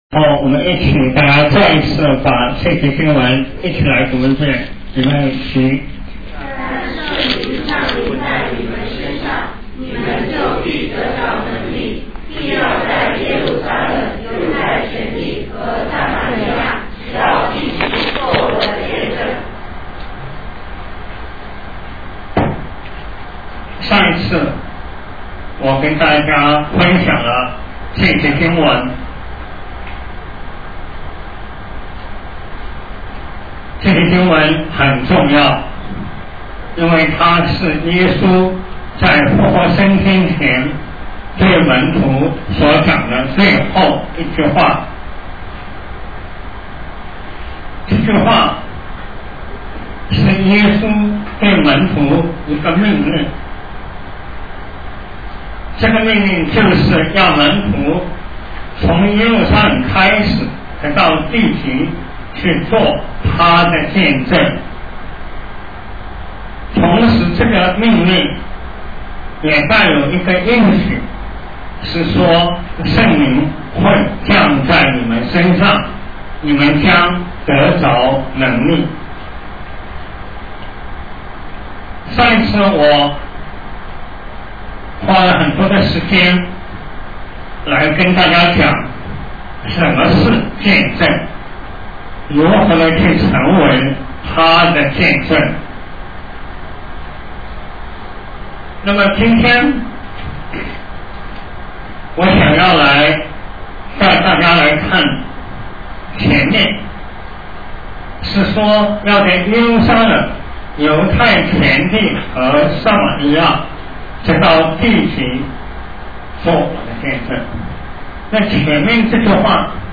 讲章：成为见证（下）（2007年10月7日，附音频）